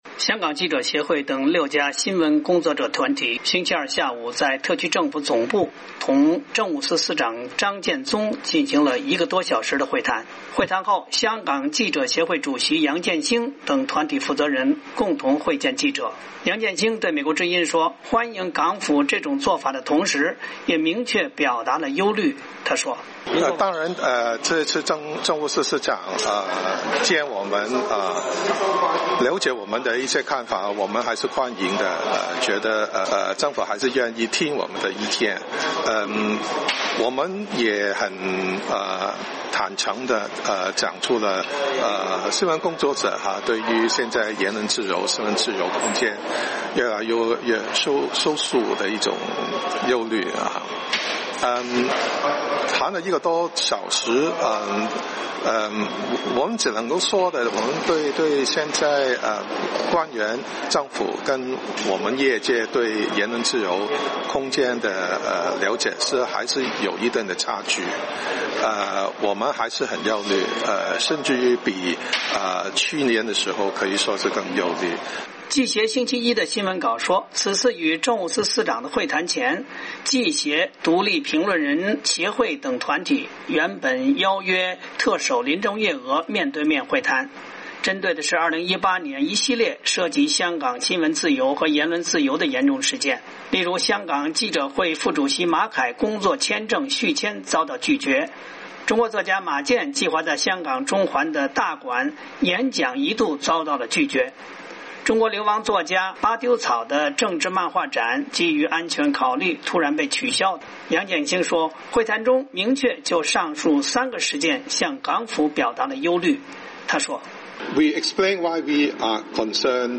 2019年1月8日香港记协等新闻工作者团体会晤政务司司长后在特区政府总部举行记者会